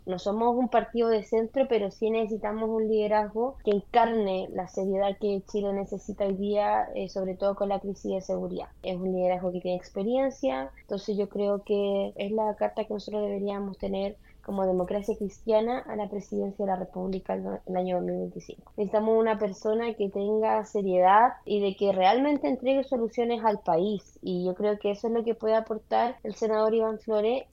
En conversación con Radio Bío Bío